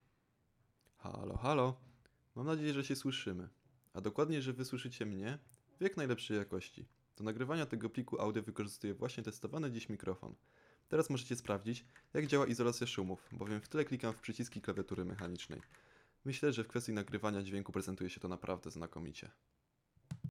• Charakterystyka kierunkowości: Superkardioidalna
Seiren V3 Chroma nagrywa głos w pełnym, naturalnym brzmieniu, z dobrze podkreślonymi średnimi i niskimi tonami, co sprawia, że głos brzmi głębiej i bardziej radiowo. Dodatkowo mikrofon nie wyłapuje niepotrzebnego pogłosu, co oznacza, że nawet w pomieszczeniu bez profesjonalnego wygłuszenia jakość nagrania stoi na wysokim poziomie.